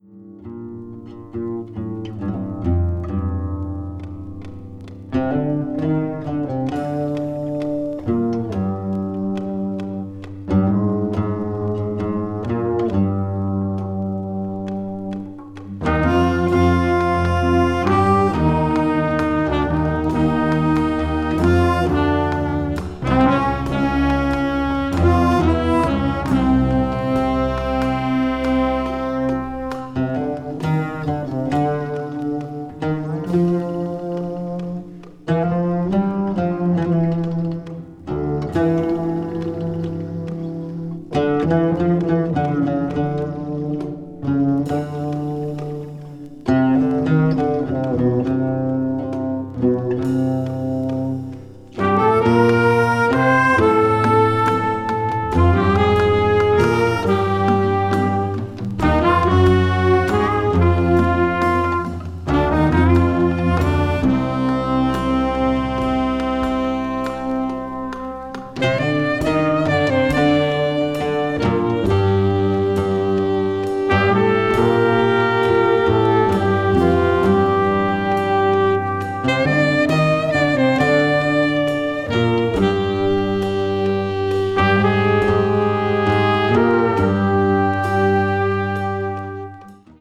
arab   contemporary jazz   ethnic jazz   world music